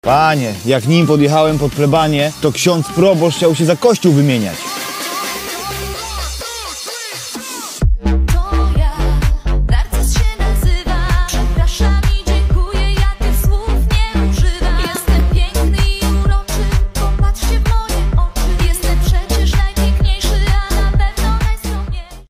Moc kontra szaleństwo! Audi RS6 sound effects free download